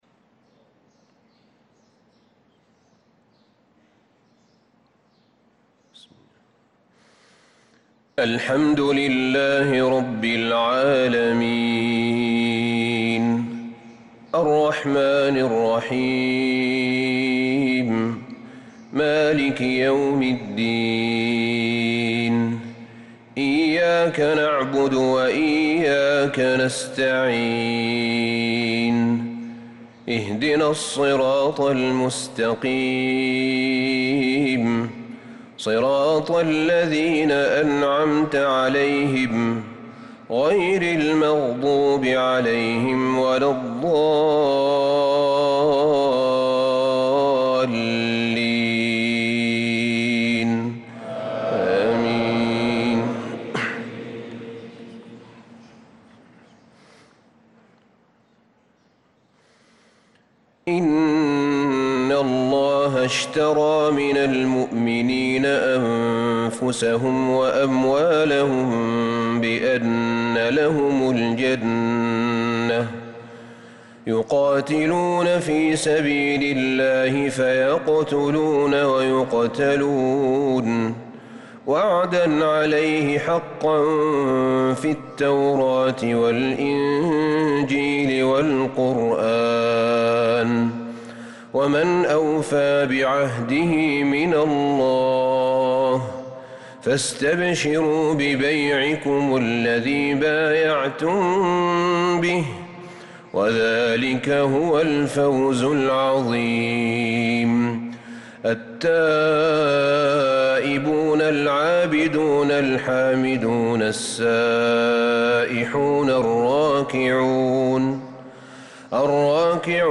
صلاة الفجر للقارئ أحمد بن طالب حميد 19 محرم 1446 هـ
تِلَاوَات الْحَرَمَيْن .